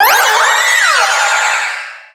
Cri de Méga-Diancie dans Pokémon Rubis Oméga et Saphir Alpha.
Cri_0719_Méga_ROSA.ogg